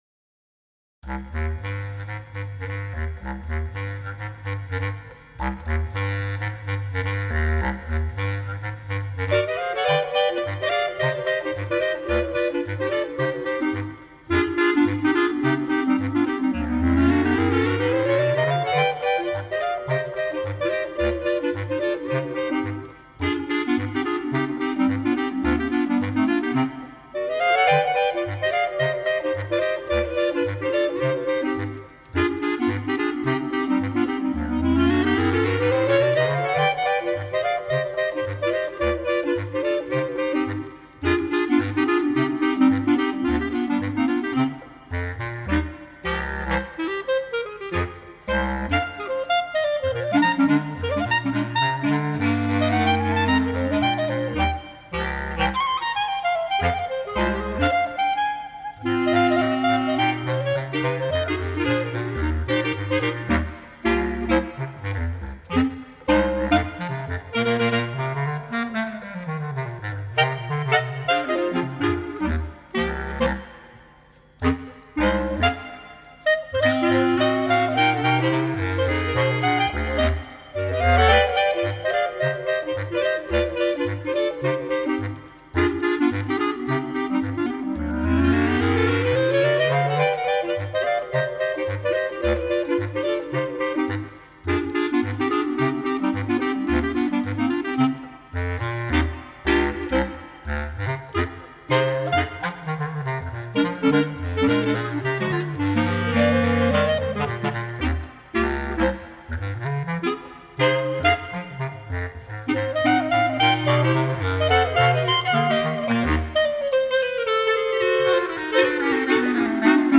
lively ragtime piece
features a written jazz clarinet solo
Bass Clarinet Lowest Note: D1 with ossias.